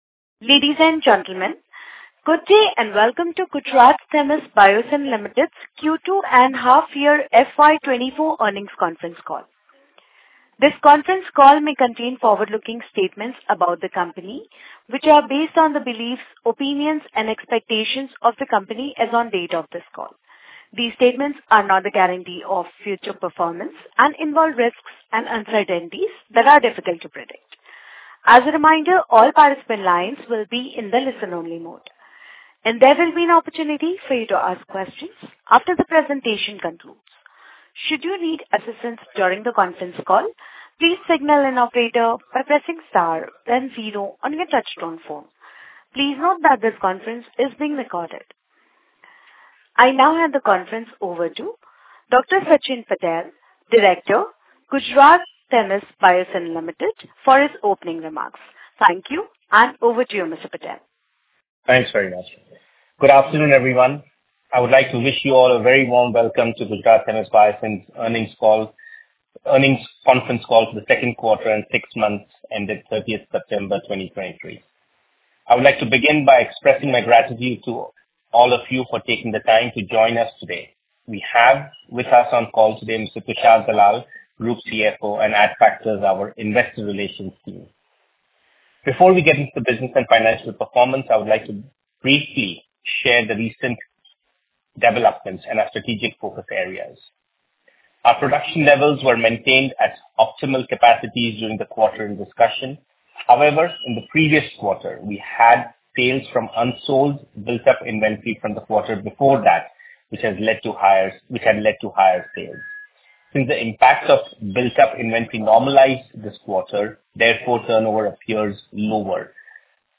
Concalls
GTBL_Q2-FY24-Earnings-Call-Audio.mp3